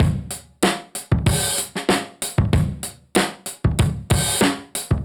Index of /musicradar/dusty-funk-samples/Beats/95bpm/Alt Sound